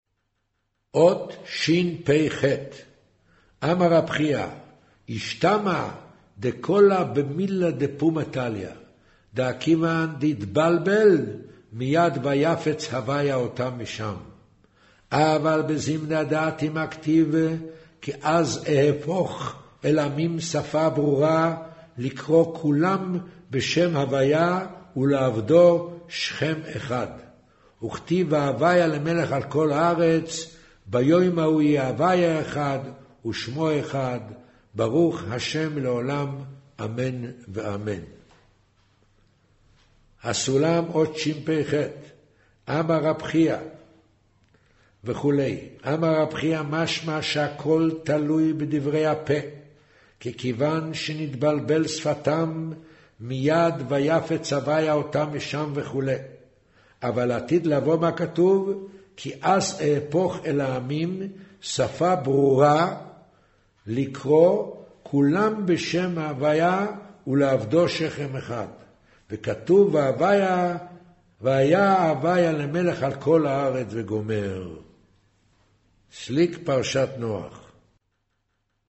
קריינות זהר פרשת נח מאמר ויאמר ה' הן עם אחד